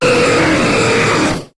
Cri de Rayquaza dans Pokémon HOME.